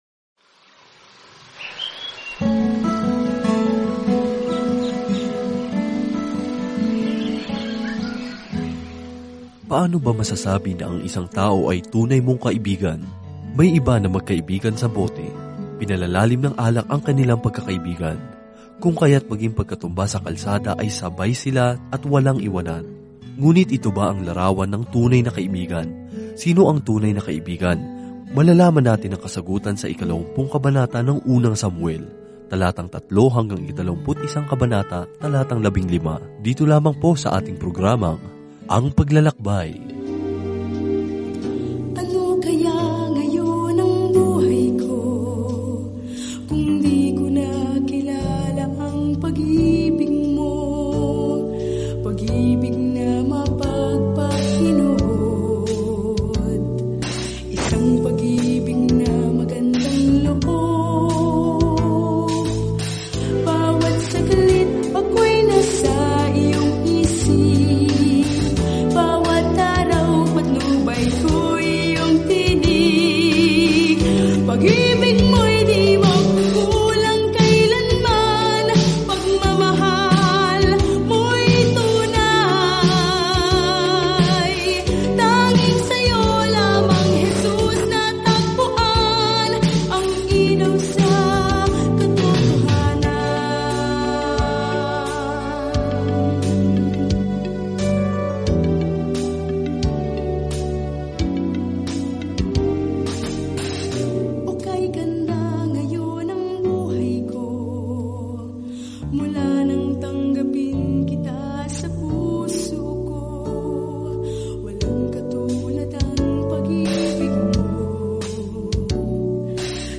Banal na Kasulatan 1 Samuel 20:3-42 1 Samuel 21 Araw 10 Umpisahan ang Gabay na Ito Araw 12 Tungkol sa Gabay na ito Nagsimula ang Unang Samuel sa Diyos bilang hari ng Israel at nagpatuloy sa kuwento kung paano naging hari si Saul noon si David. Araw-araw na paglalakbay sa Unang Samuel habang nakikinig ka sa audio study at nagbabasa ng mga piling talata mula sa salita ng Diyos.